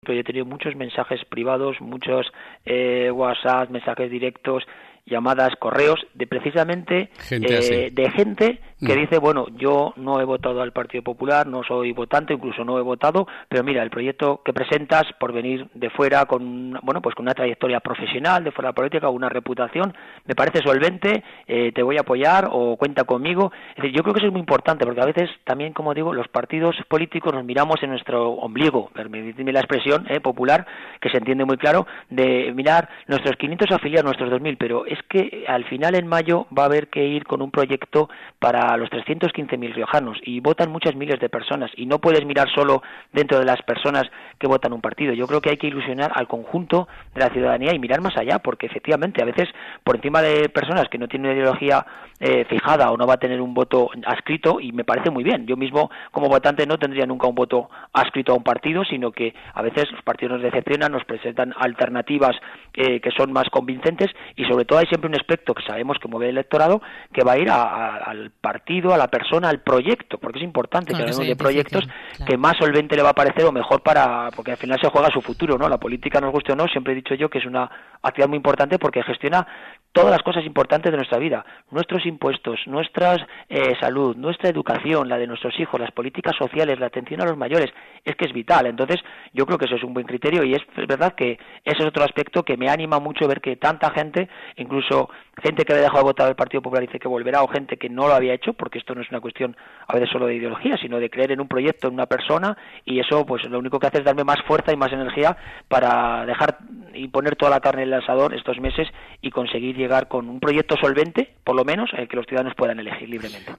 Gonzalo Capellán, en una entrevista, se ha referido a los mensajes de ánimo y apoyo que está recibiendo.